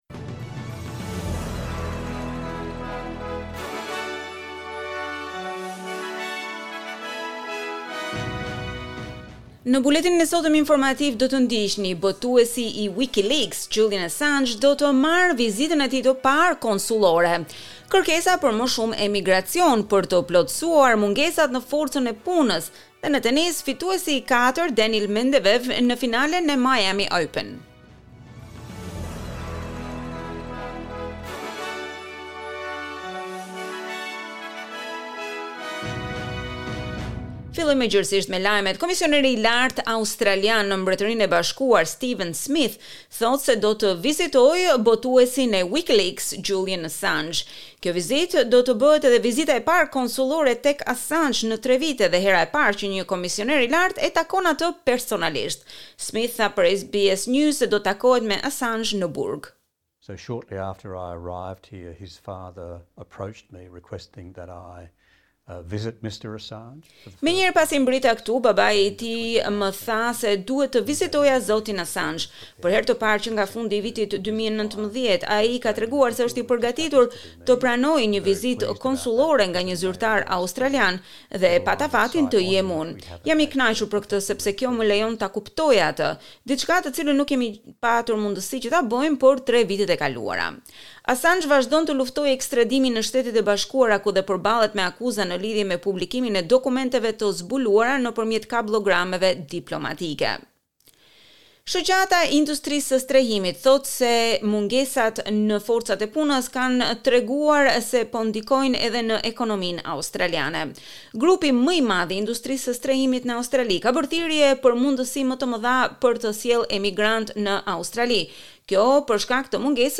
SBS News Bulletin - 1 April 2023